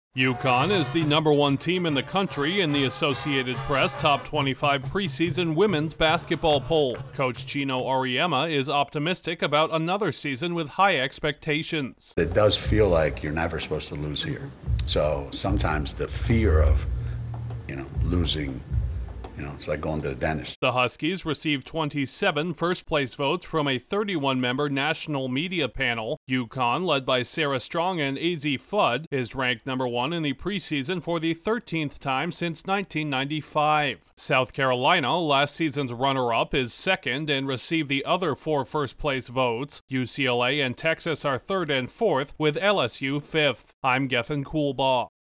The defending and 12-time champion Connecticut Huskies are leading the way into another season of women’s college basketball. Correspondent